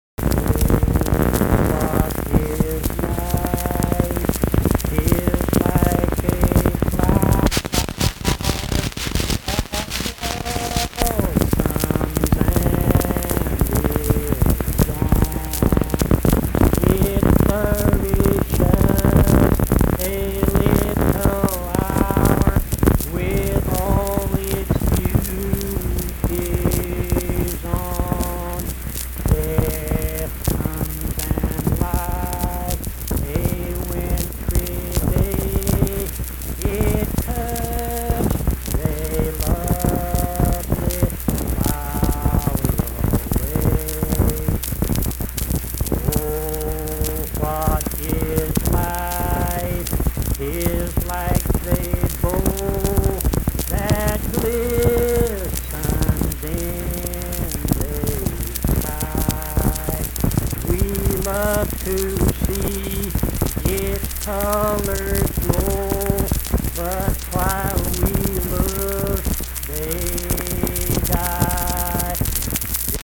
Accompanied (guitar) and unaccompanied vocal music
Verse-refrain 2(6). Performed in Mount Harmony, Marion County, WV.
Hymns and Spiritual Music
Voice (sung)